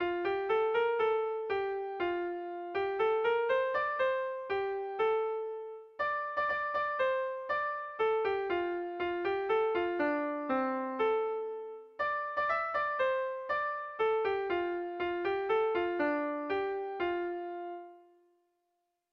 Erromantzea
7A / 7B / 7A / 7B / 7B / 7B
ABD